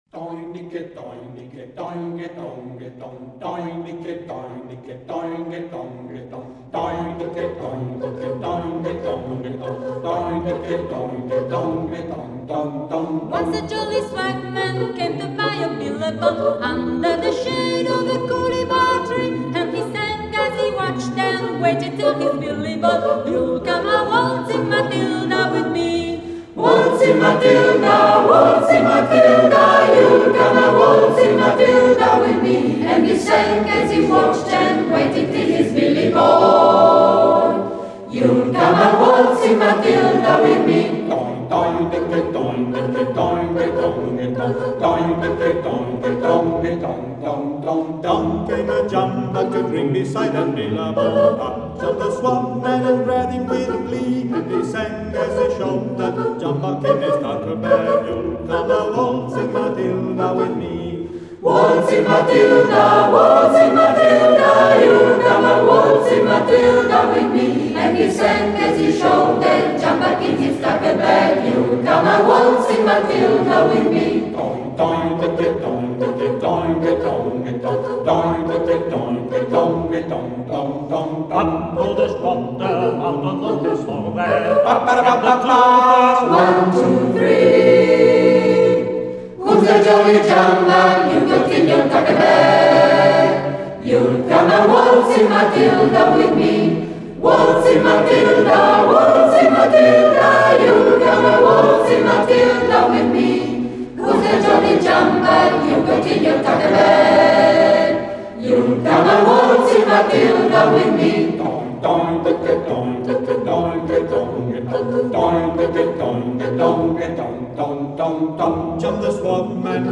Compositore: Paterson, Andrew Barton (Banjo)
Esecutore: Coro Nigritella